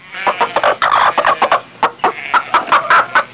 Chicken Sound Effect
Download a high-quality chicken sound effect.
chicken-2.mp3